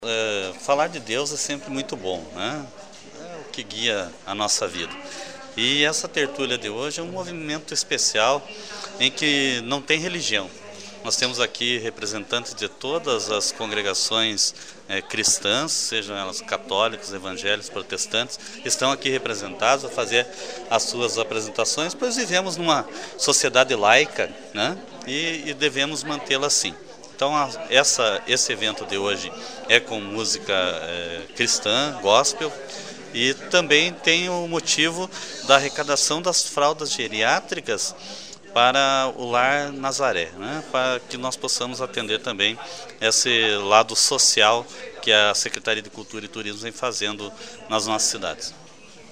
O secretario de Cultura e Turismo de Porto União José Carlos Gonçalves, fez questão de destacar a Tertúlia, onde o espaço é oferecido para apresentações da comunidade em especial nesta noite para os grupos e pessoas que realizaram os cantos religiosos. Outro ponto destacado pelo secretario foi à doação de fraldas geriátricas, que serão doadas para o Lar de Nazaré, que atende vários idosos.
JOSÉ-CARLOS-FALA-DA-TERTÚLIA-REALIZADA.mp3